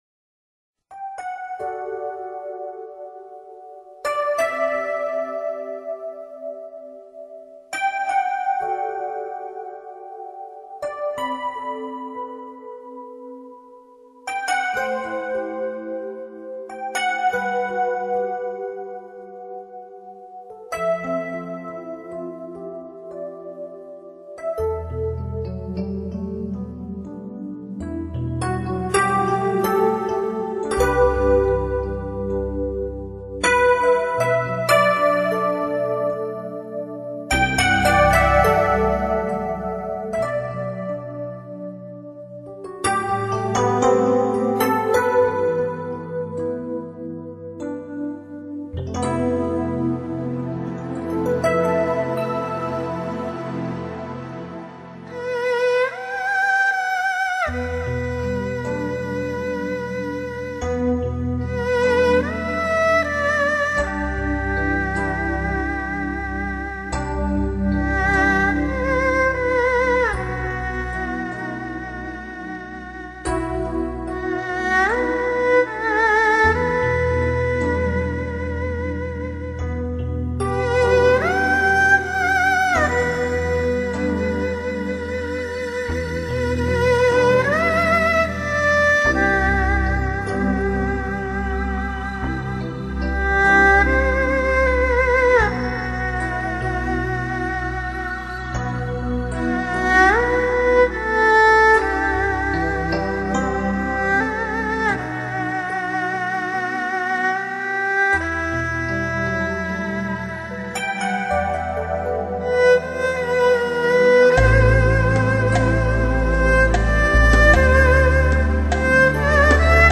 音乐类型：China-Pop
与电子乐结合的二胡，有了更强、更新的表现力，二胡本真承载的旷古忧伤，二胡具有的民族风韵。